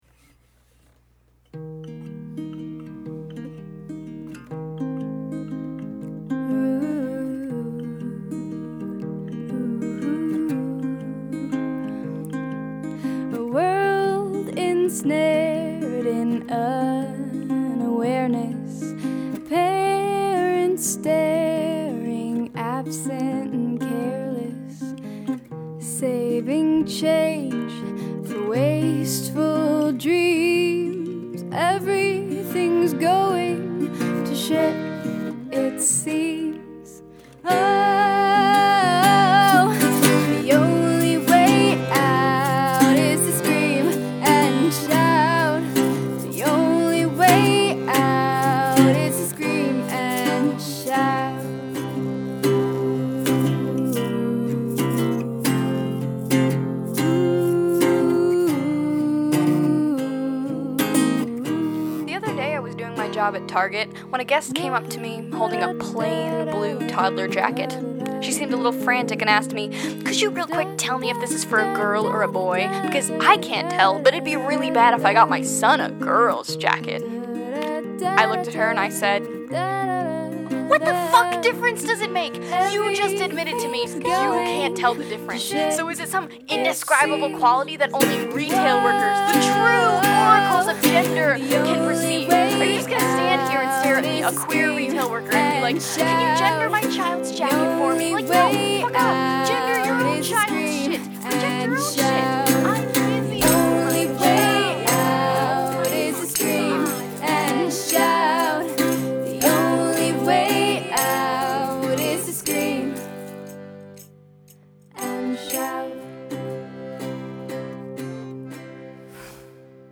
Downward Modulation